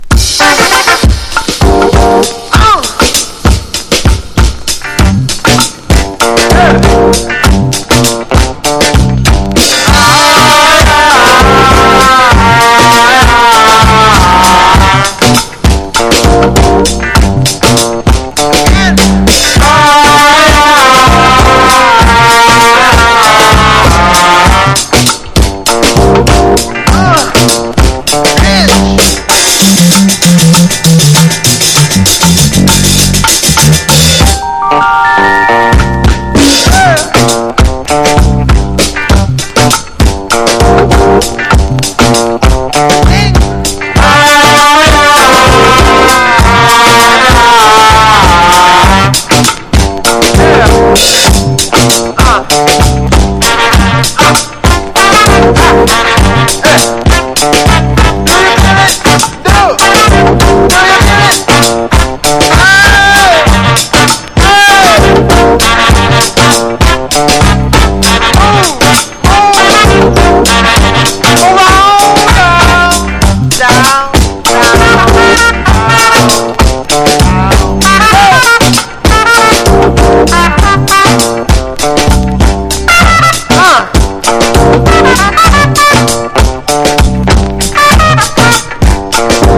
ロウなアフロビートにルーディーな雄叫びコーラスと渋いホーン・ソロが気分を高揚させる最高の1曲！
AFRO# FUNK / DEEP FUNK